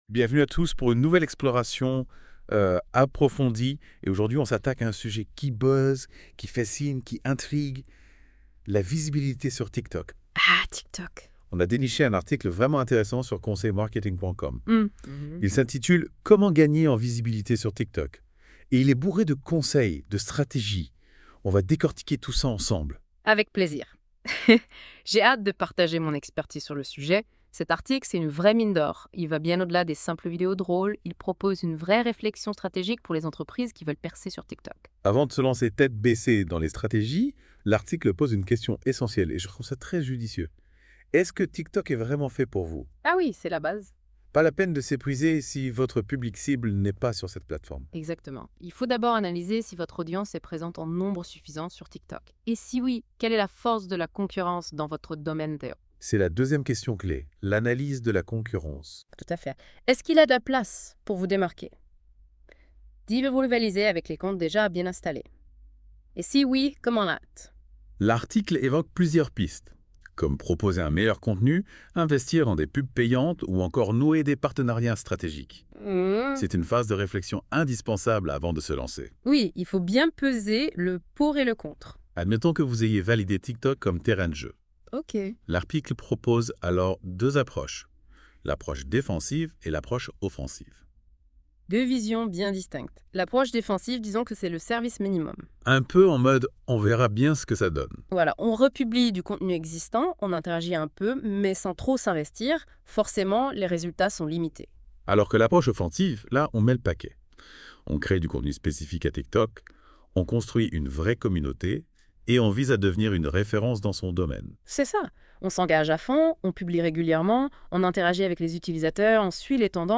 6 conseils pour gagner en visibilité sur Tiktok [Article de Blog résumé par Notebook LM]